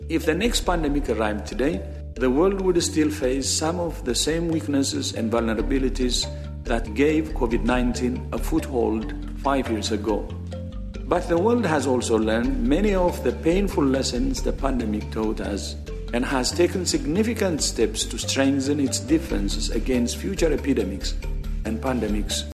W-H-O Director-General Dr Tedros Adhanom Ghebreyesus, says we are better prepared for the next pandemic: